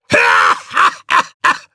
Phillop-Vox_Happy3_jp.wav